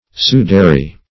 Sudary - definition of Sudary - synonyms, pronunciation, spelling from Free Dictionary
Search Result for " sudary" : The Collaborative International Dictionary of English v.0.48: Sudary \Su"da*ry\, n. [L. sudarium, fr. sudare to sweat.